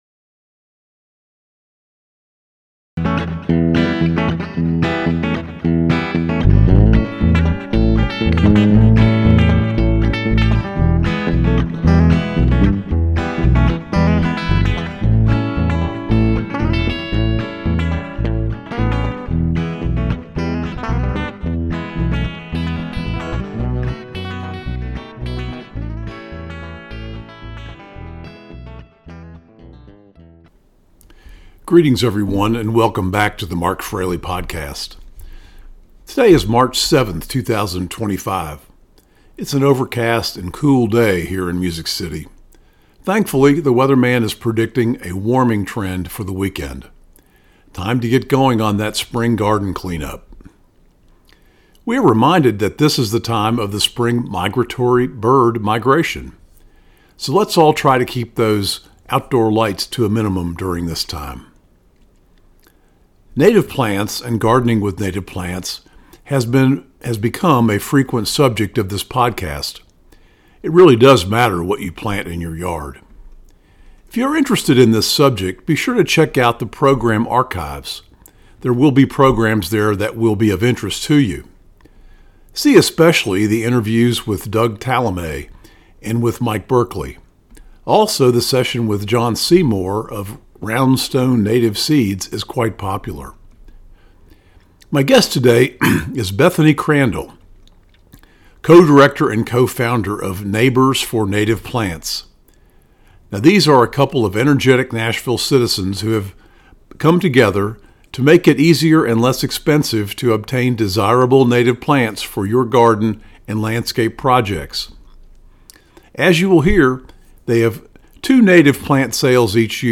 Neighbors4nativesinterview-with-music-and-effects.mp3